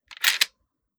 Foley